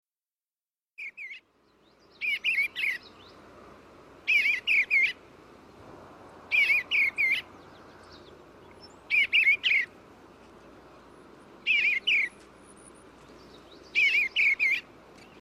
bluebird.mp3